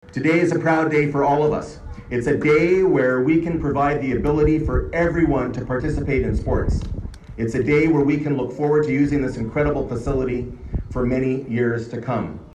It was sunshine and blue skies on a joyful Saturday as the Field of Ability at Parkdale Veterans Park in Belleville was officially opened.